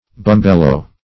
Bumbelo \Bum"be*lo\, n.; pl. Bumbeloes. [It. bombola.]